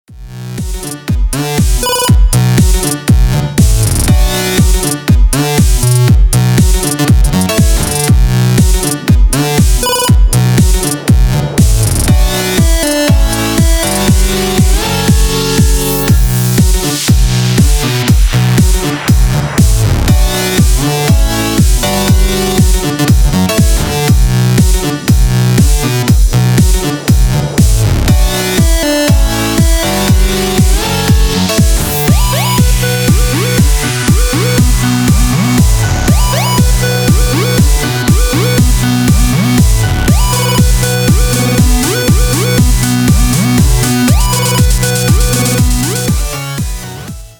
• Качество: 320, Stereo
без слов
Стиль: Electro house